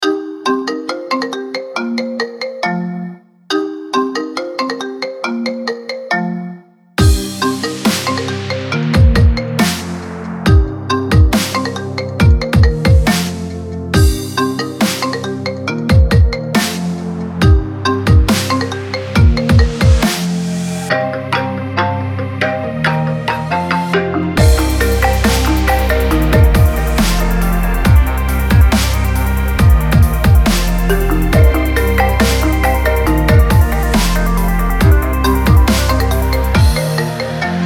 • Качество: 320, Stereo
громкие
remix
мелодичные
без слов